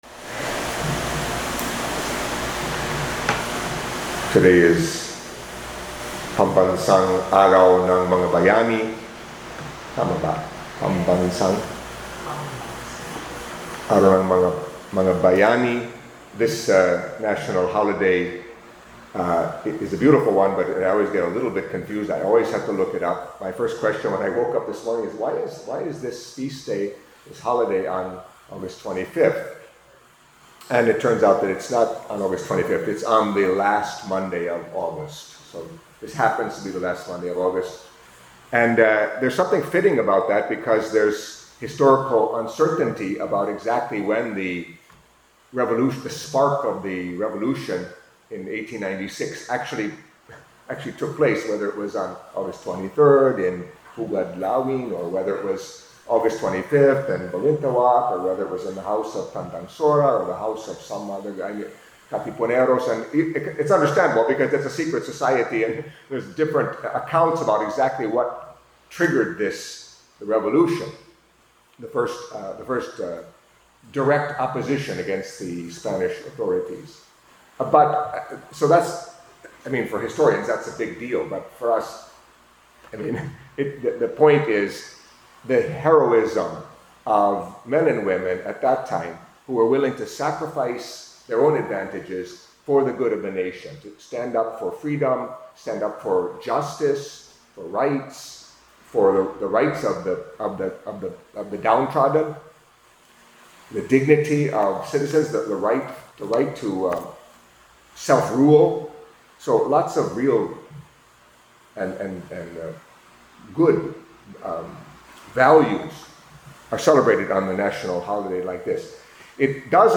Catholic Mass homily for Monday of the Twenty-First Week in Ordinary Time